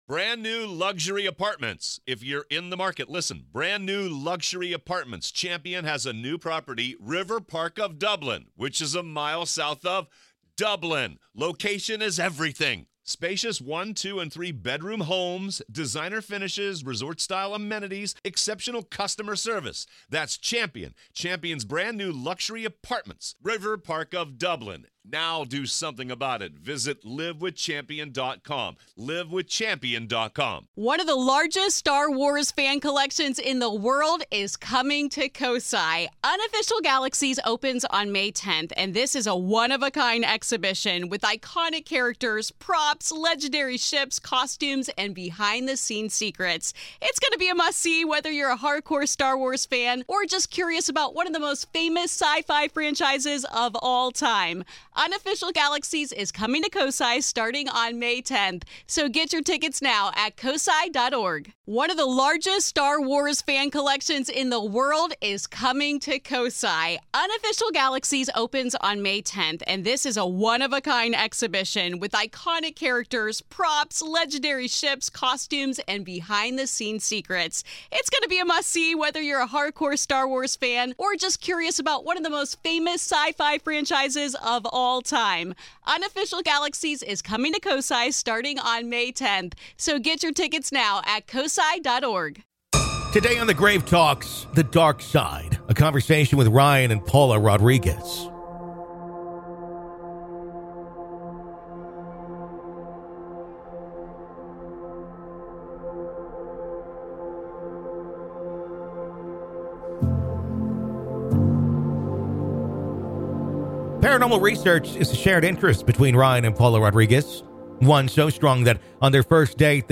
LISTEN HERE In part two of our interview